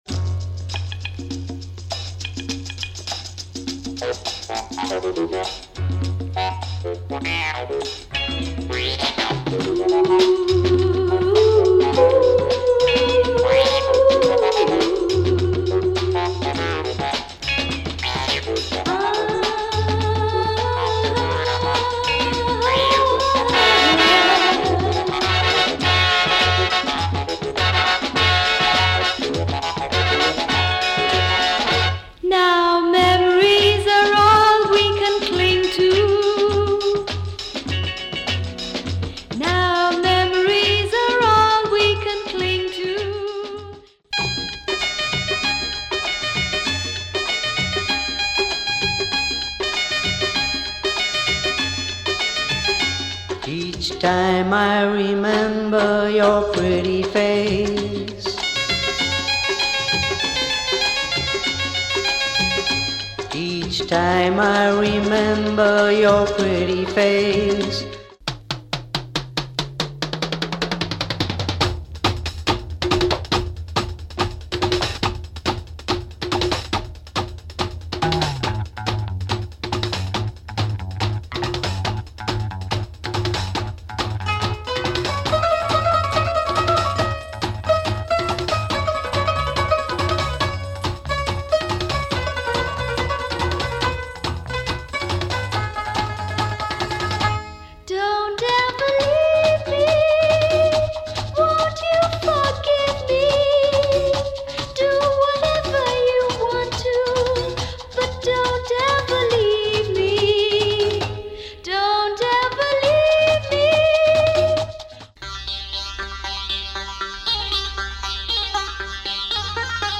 Bollywood gone Oriental !